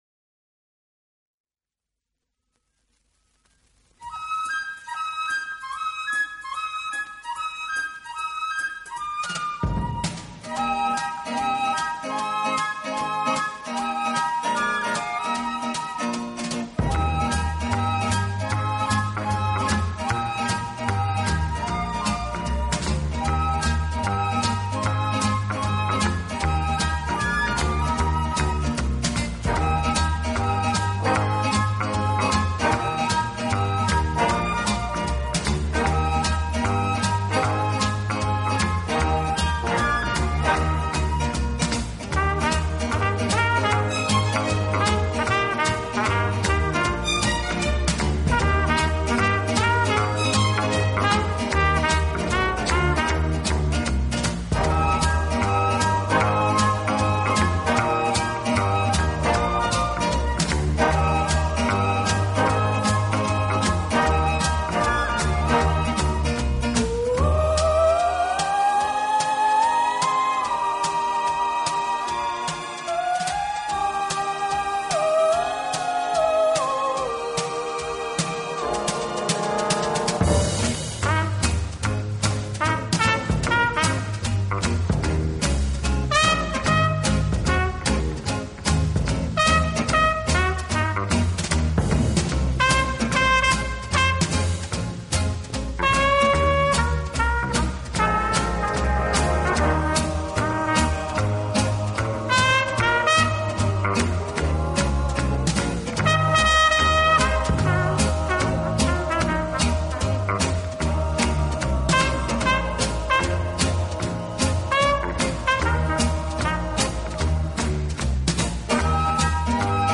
【轻音乐】
小号的音色，让他演奏主旋律，而由弦乐器予以衬托铺垫，音乐风格迷人柔情，声情并
茂，富于浪漫气息。